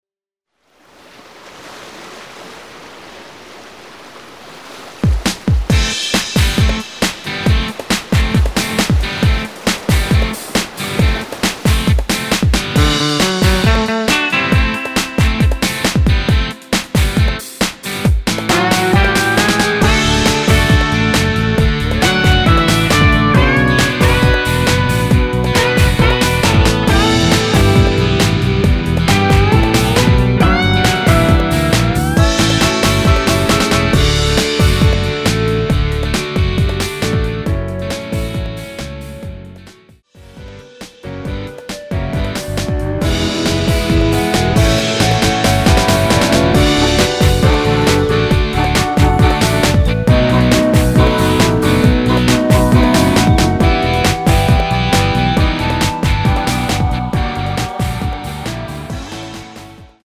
원키 멜로디 포함된 MR 입니다.(미리듣기 참조)
앨범 | O.S.T
앞부분30초, 뒷부분30초씩 편집해서 올려 드리고 있습니다.
중간에 음이 끈어지고 다시 나오는 이유는